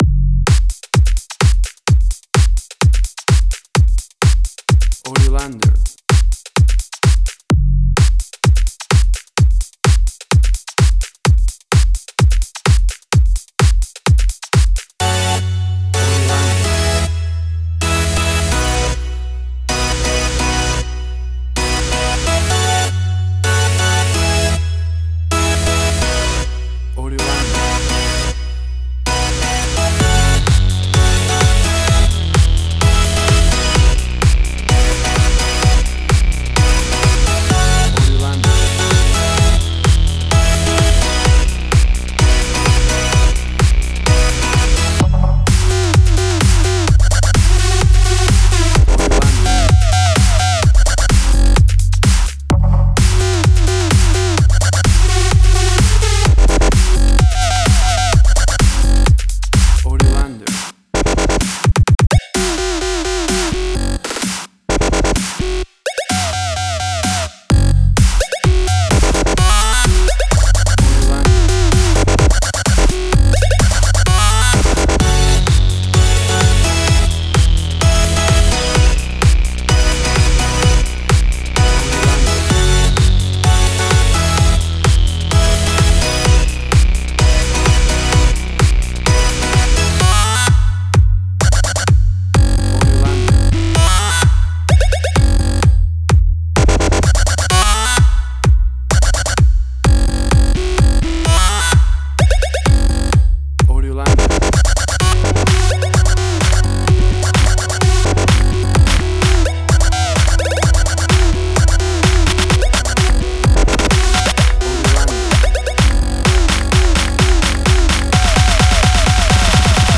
Tempo (BPM): 128